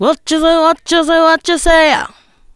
HIP HOP 1.wav